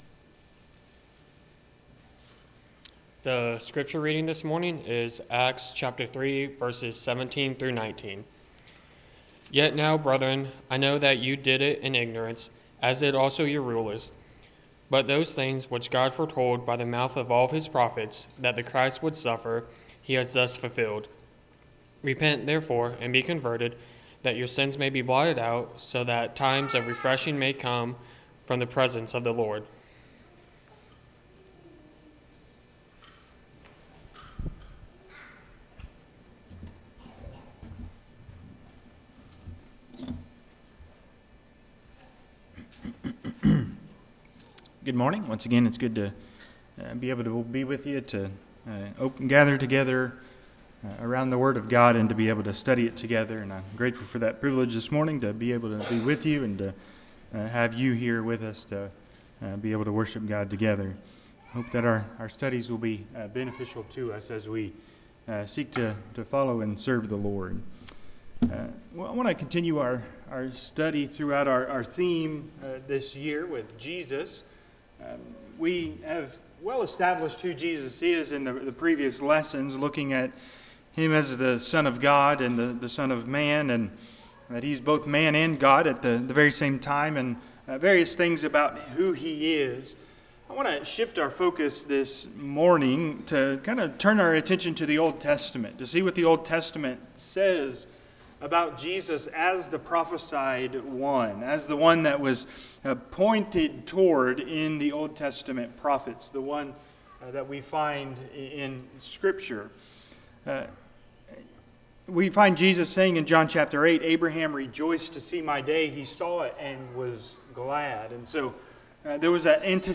Acts 3:17-19 Service Type: Sunday AM Topics